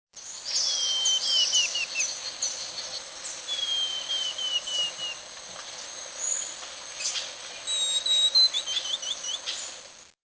Pachyramphus cinnamomeus (cinnamon becard)
Here are a few examples of the call and song of the Cinnamon Becard (Pachyramphus cinnamomeus), recorded while the bird sat high in a tree early morning - El Gavilan - in the river bottom (Costa Rica). 11/16/98.